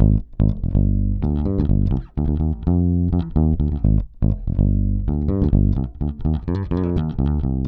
Index of /90_sSampleCDs/Best Service ProSamples vol.48 - Disco Fever [AKAI] 1CD/Partition D/BASS-FINGER